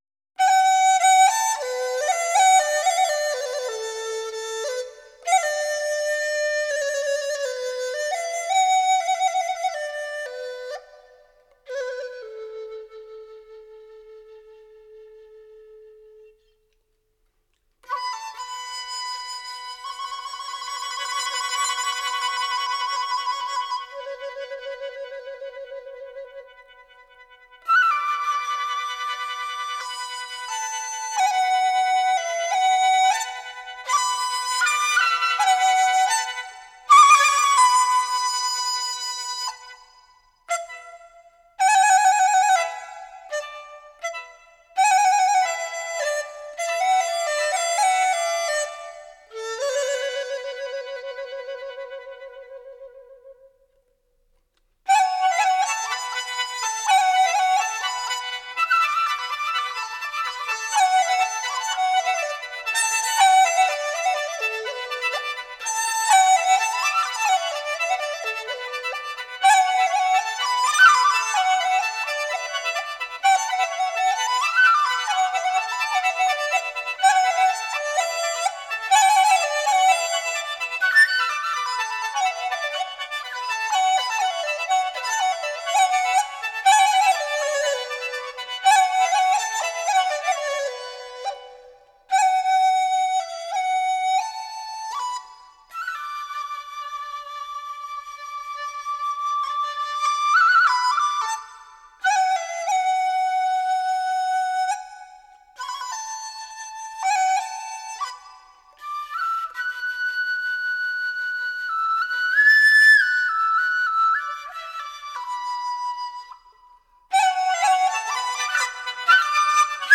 0269-小放牛 笛子.mp3